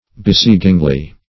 -- Be*sie"ging*ly , adv.